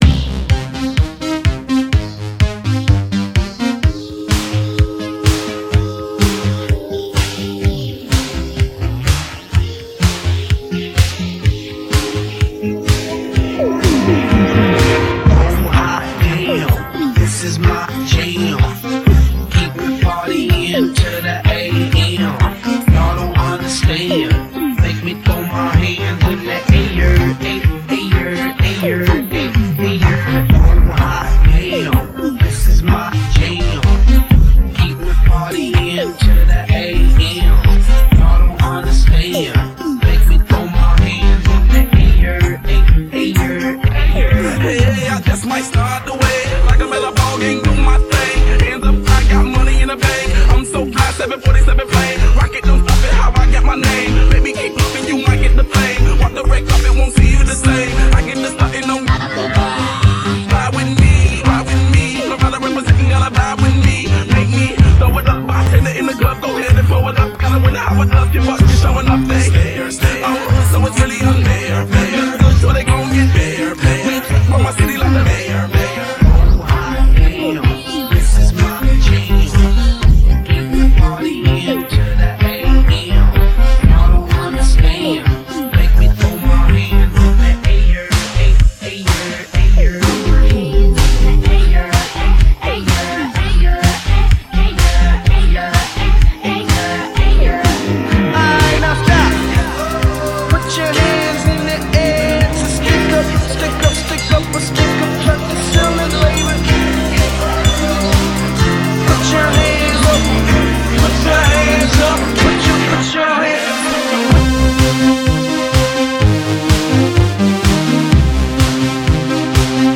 These are unauthorized bootlegs.
Genres: Hip Hop, Rock, Top 40